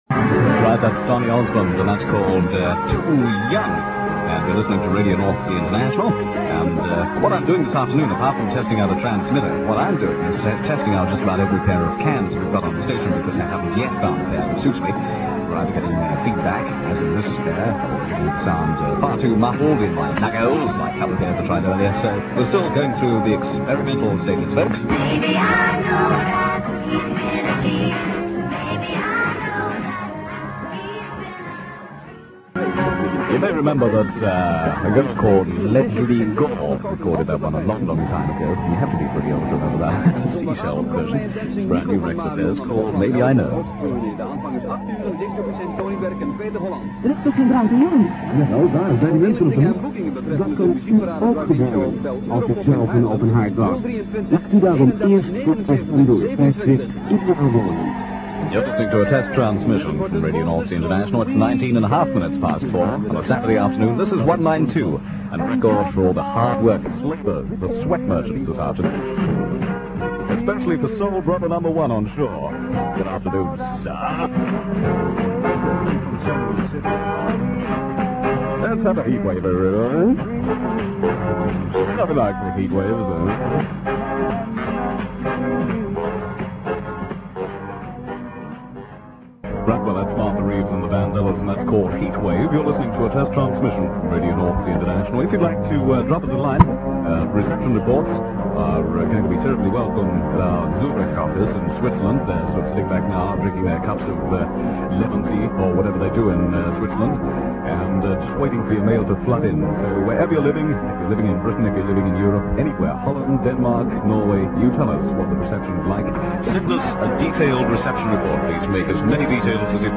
There was noticeable audio breakthrough from the Dutch service whose programmes could be heard clearly in the background.
RNI 2, with the breakthrough of the Dutch Service very noticeable in the background